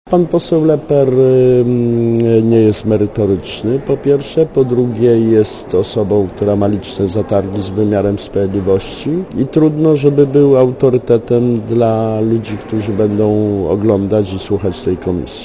Mówi Krzysztof Janik